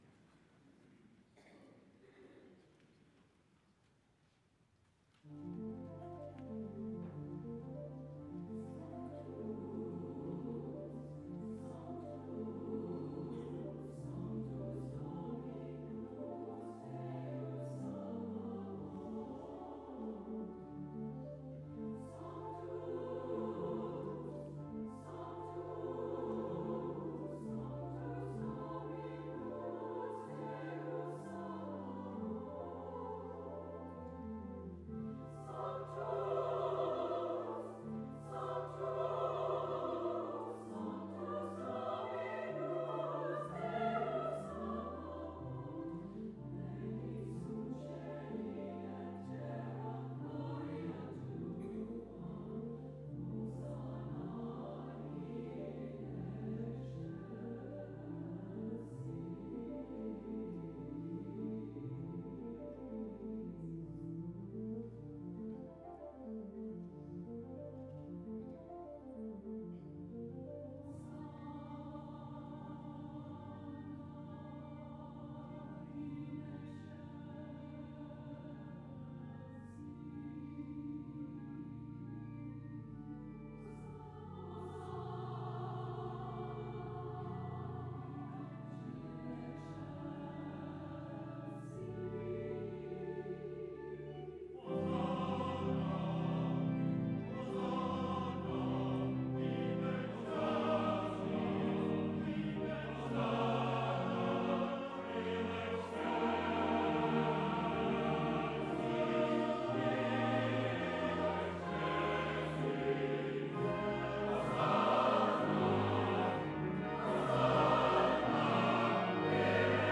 If you like sacred choral music, here is a
Sanctus that I really enjoyed (performed by our chancel choir in Sunday service on Oct 21). 8 meg .mp3 file.